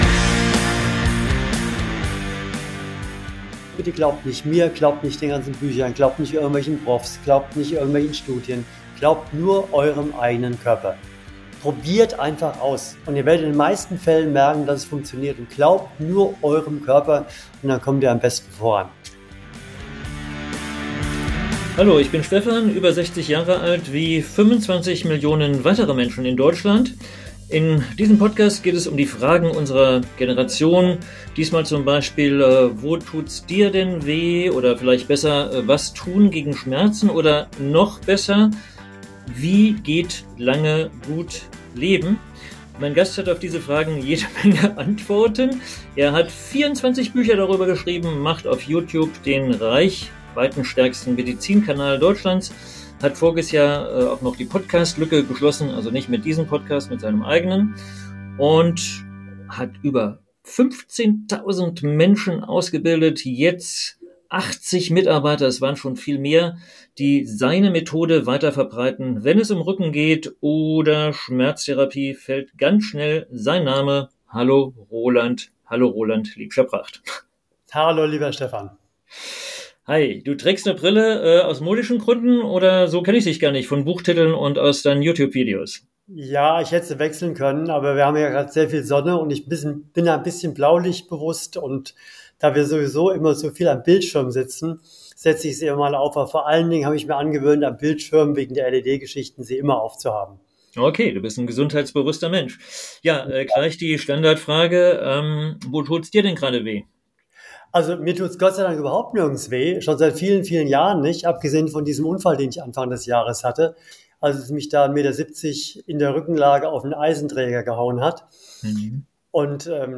Roland spricht mit mir über die größten Gesundheits-Irrtümer, über Bewegung statt Medikamente – und darüber, warum wir gerade jetzt anfangen sollten, auf unseren Körper zu hören. Was hilft wirklich, um fit und schmerzfrei zu bleiben?